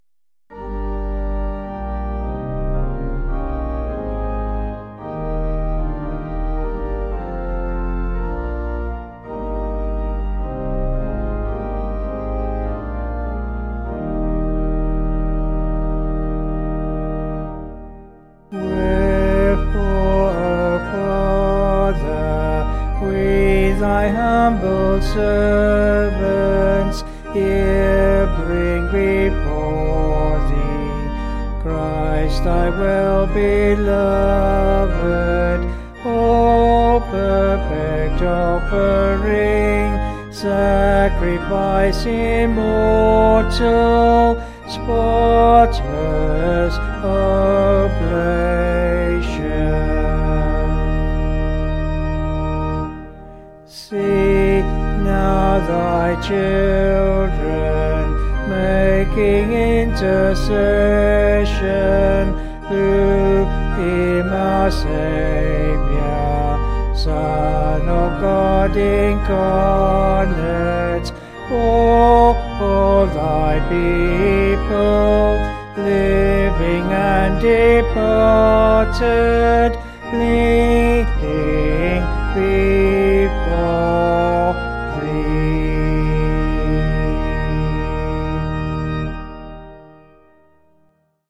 Vocals and Organ   264.2kb Sung Lyrics